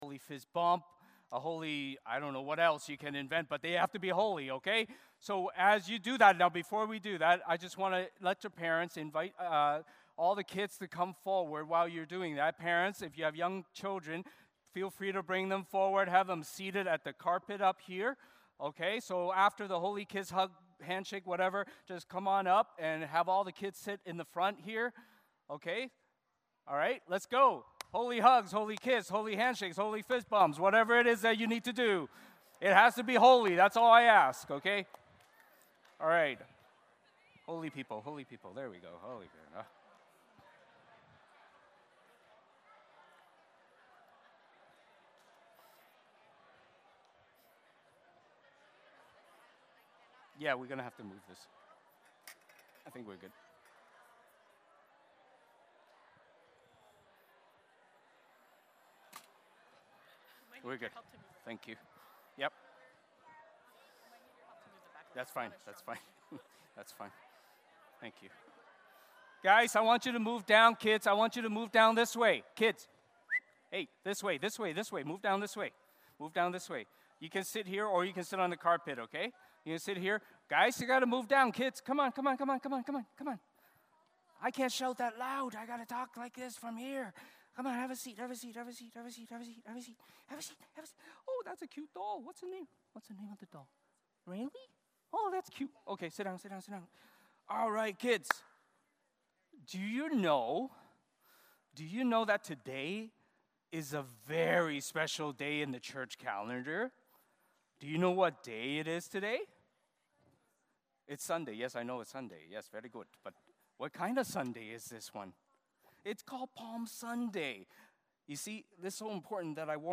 Matthew 21:1-17 Service Type: Sunday Morning Service Passage